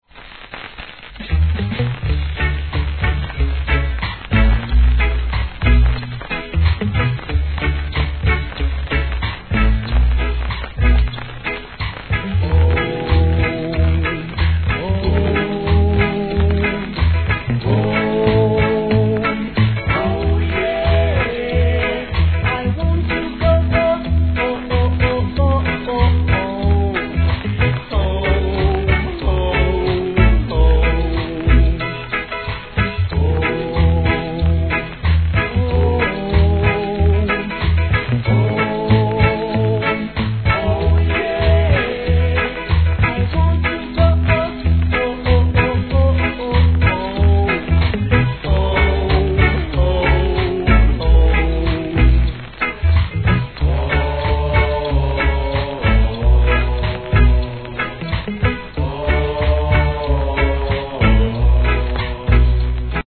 REGGAE
たまらないヴォーカルです♪